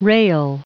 Prononciation du mot rail en anglais (fichier audio)
Prononciation du mot : rail